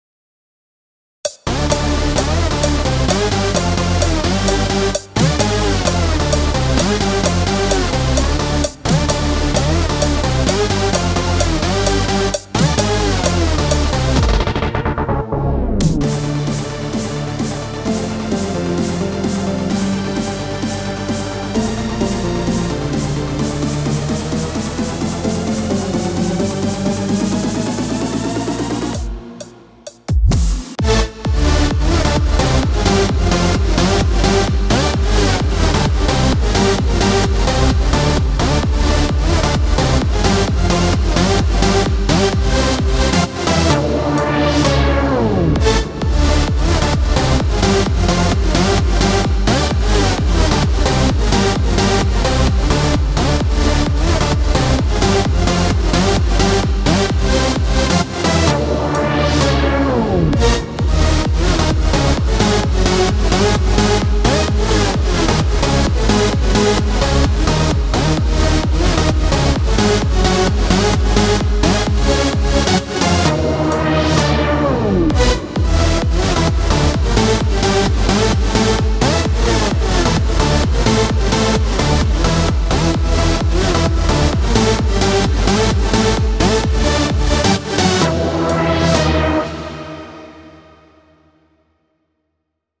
אתה על ימאהה אם הבנתי נכון?